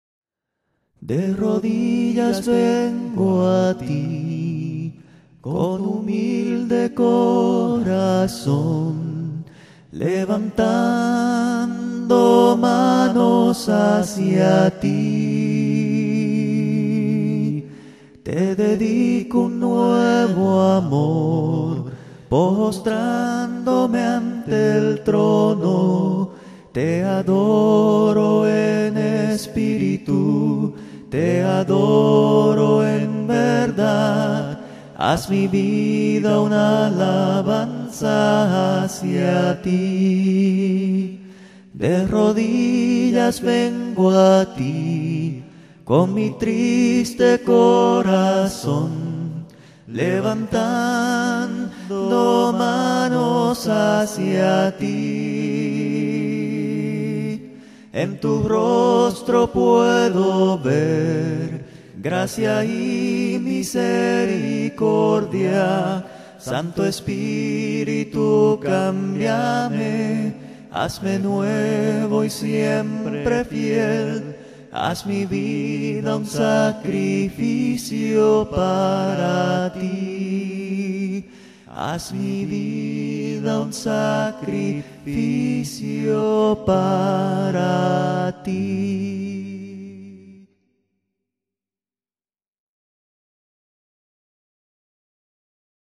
Cánticos Cristianos A Cappella, Gratis
Con Múltiples Voces: